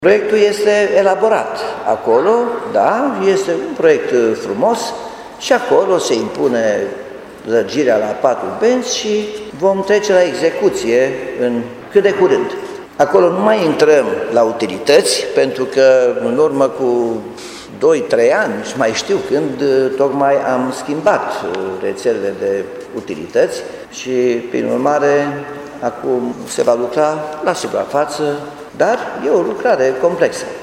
Muncitorii vor începe lucrările de extindere a fostei străzi Lidia la patru benzi de circulație, a anunțat primarul Nicolae Robu.